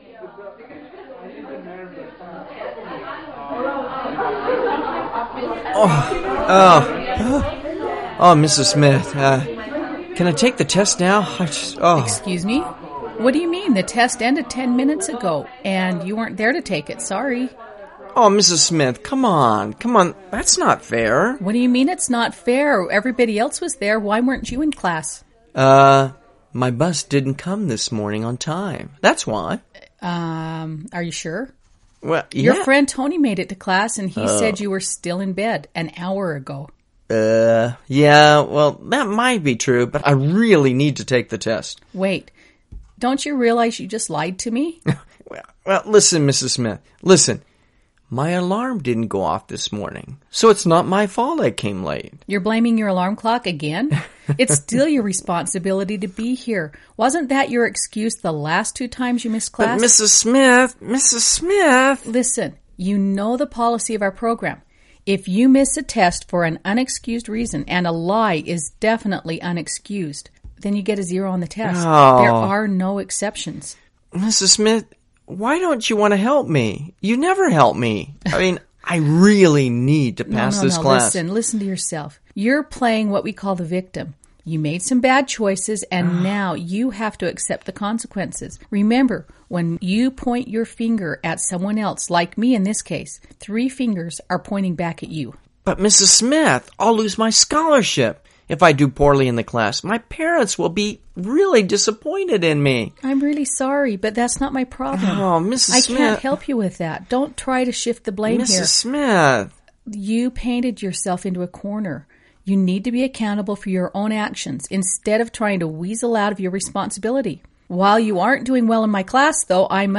【听英文对话做选择】商务会议 听力文件下载—在线英语听力室